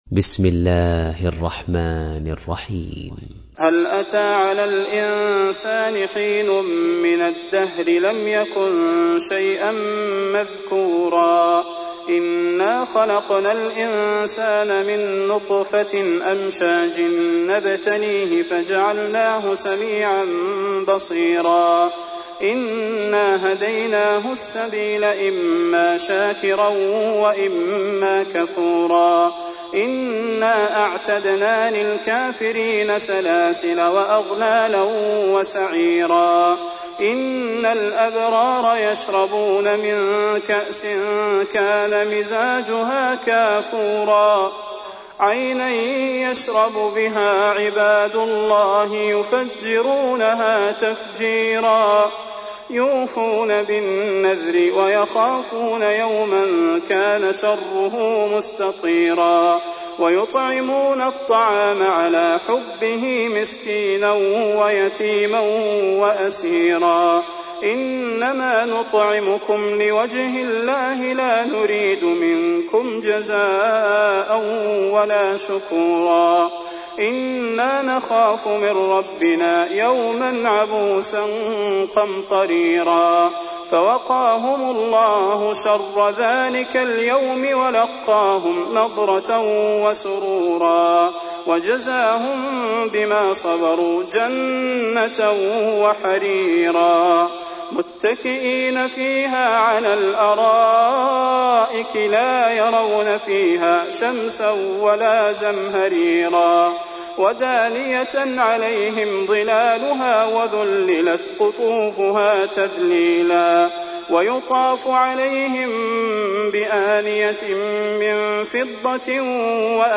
تحميل : 76. سورة الإنسان / القارئ صلاح البدير / القرآن الكريم / موقع يا حسين